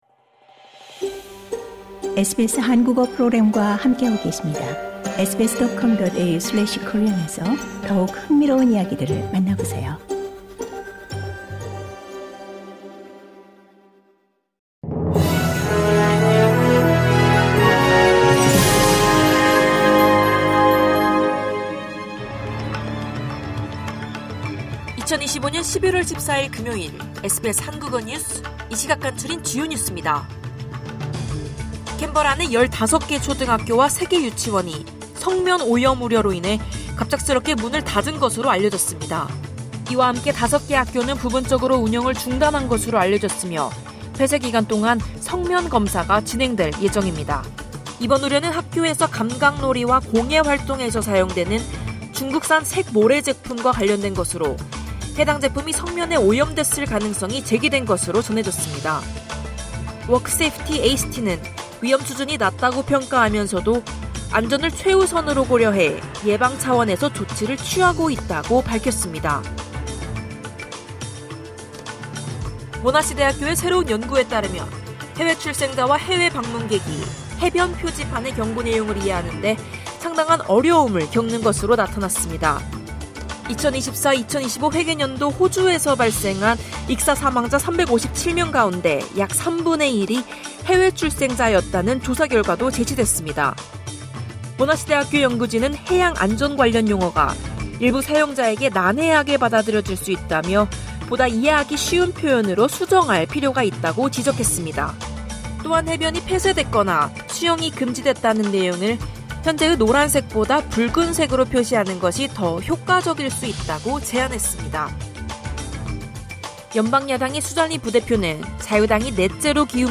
호주 뉴스 3분 브리핑: 2025년 11월 14일 금요일